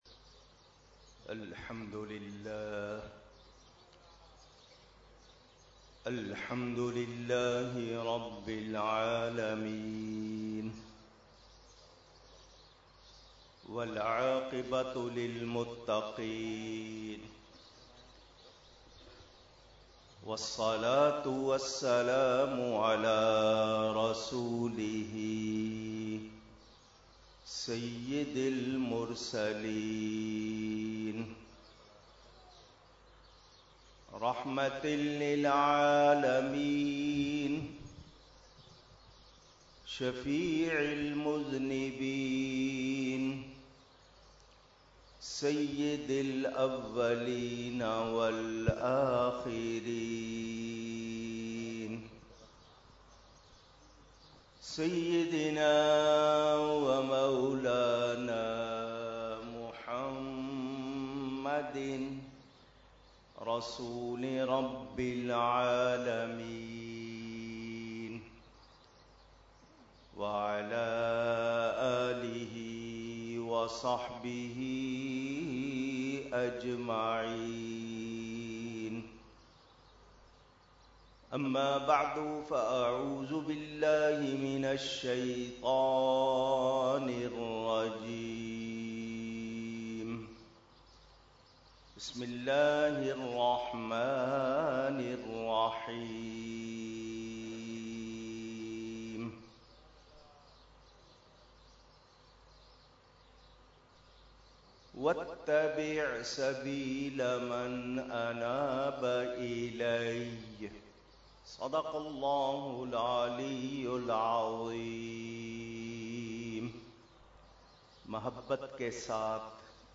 Category : Speech | Language : UrduEvent : Urs Qutbe Rabbani 2017
12-Speech By Allama Kokab Noorani Okarvi.mp3